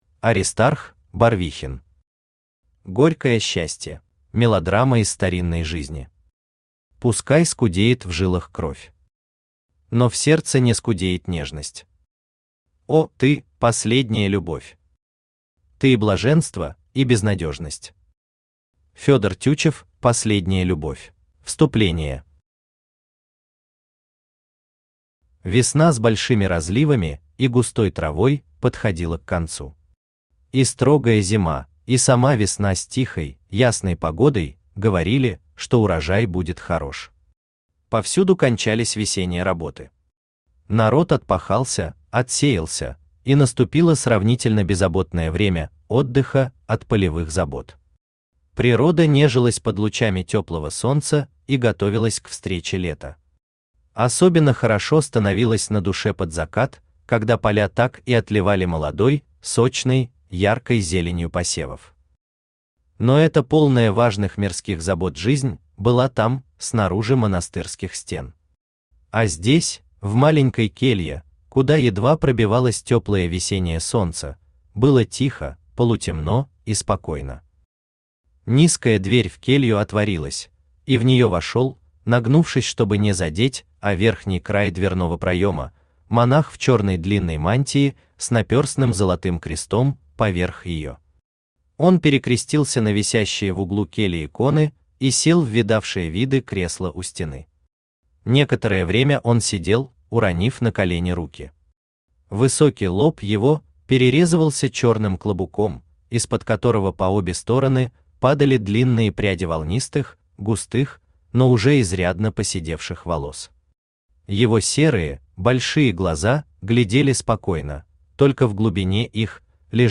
Аудиокнига Горькое счастье | Библиотека аудиокниг
Aудиокнига Горькое счастье Автор Аристарх Барвихин Читает аудиокнигу Авточтец ЛитРес.